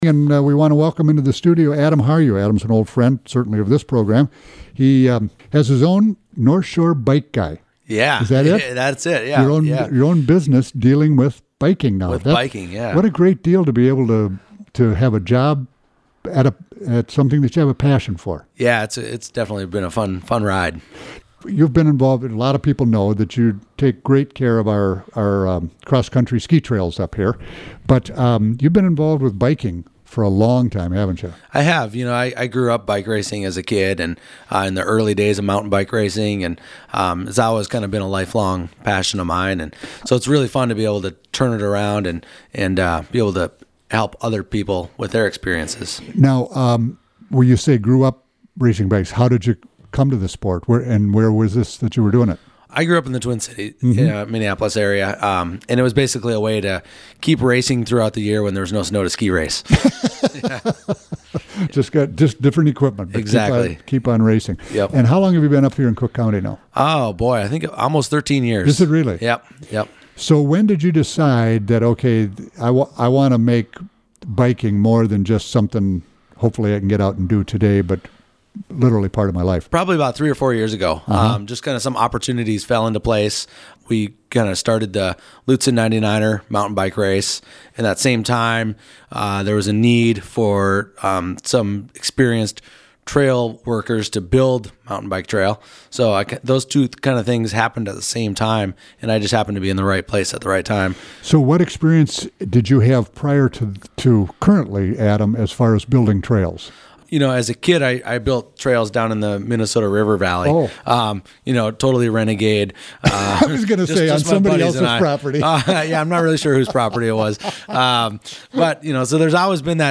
" stopped in to Studio A May 24 to talk all things bike. He does bike trail building, event promotion, advocacy for cycling, and more.